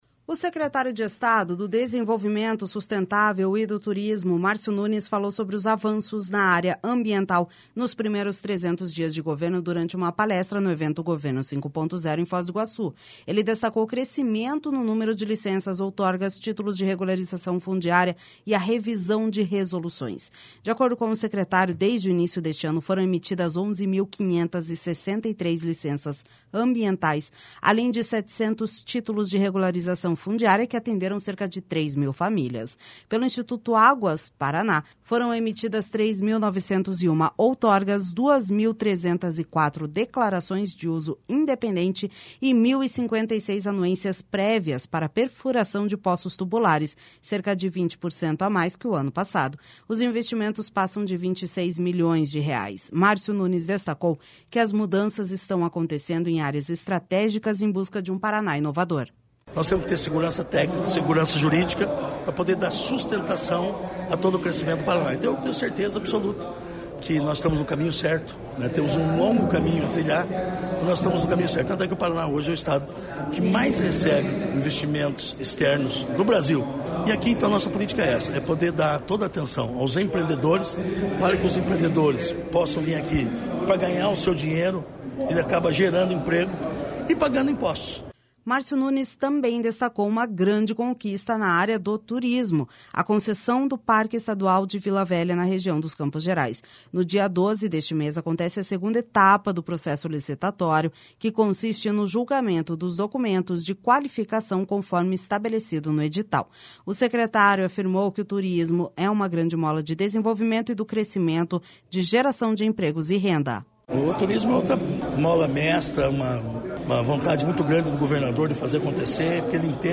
O secretário de Estado do Desenvolvimento Sustentável e do Turismo, Márcio Nunes, falou sobre os avanços na área ambiental nos primeiros 300 dias de governo, durante uma palestra no evento Governo 5.0, em Foz do Iguaçu. Ele destacou o crescimento no número de licenças, outorgas, títulos de regularização fundiária, e a revisão de resoluções.